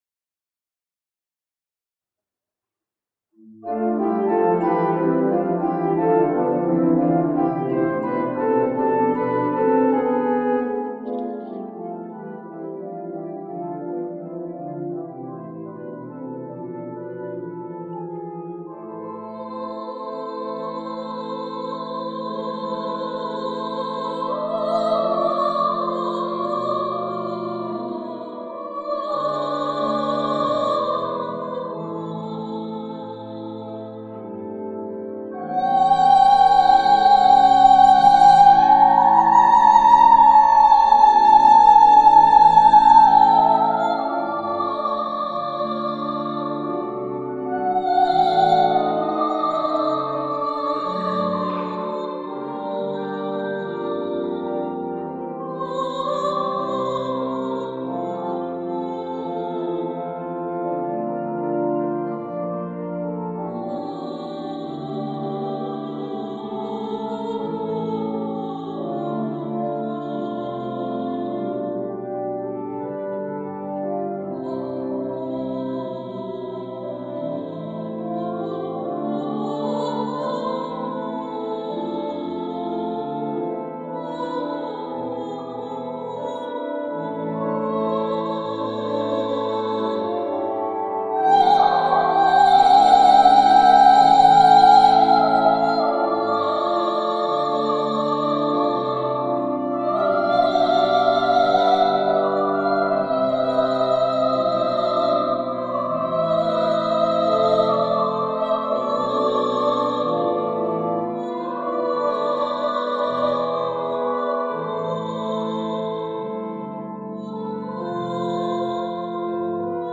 In einer Fassung für Orgel und Sopran